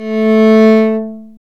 Index of /90_sSampleCDs/Roland L-CD702/VOL-1/STR_Viola Solo/STR_Vla3 _ marc
STR VIOLA 04.wav